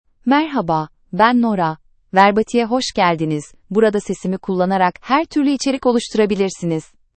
Nora — Female Turkish (Turkey) AI Voice | TTS, Voice Cloning & Video | Verbatik AI
Nora is a female AI voice for Turkish (Turkey).
Voice sample
Listen to Nora's female Turkish voice.
Female
Nora delivers clear pronunciation with authentic Turkey Turkish intonation, making your content sound professionally produced.